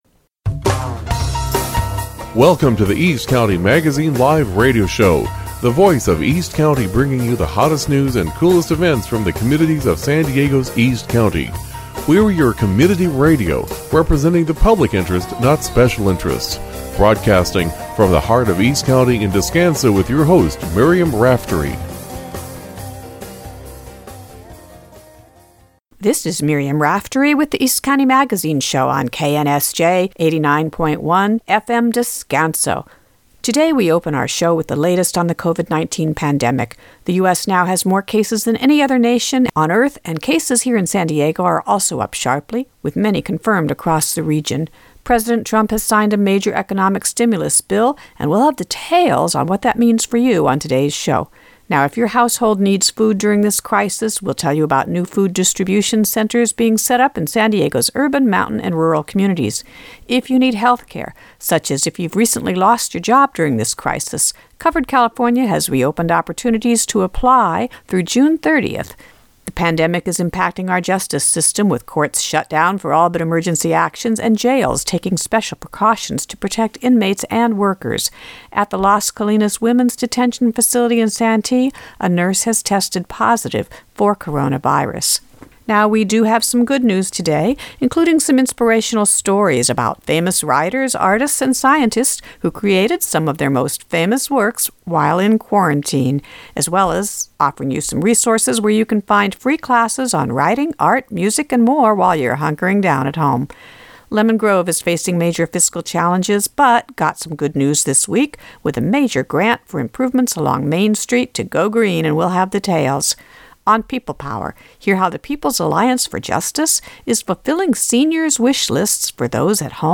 Our radio shows from January, February, March and April 2020 are now online.